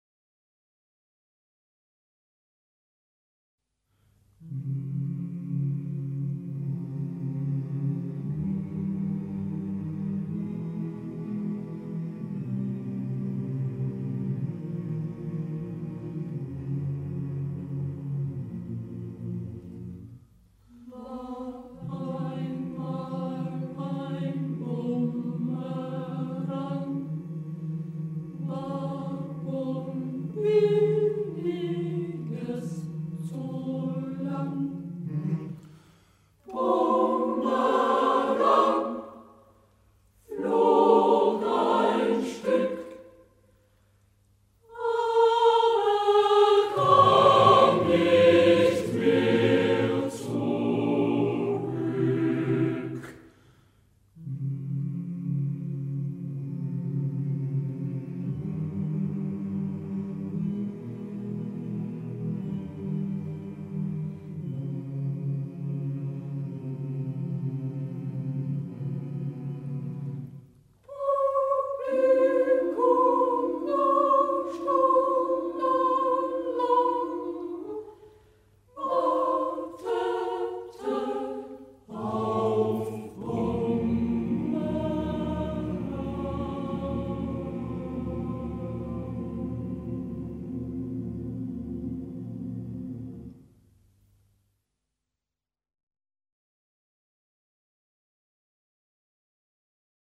�sterreichische Chormusik